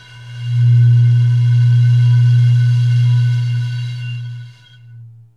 WINE GLAS00R.wav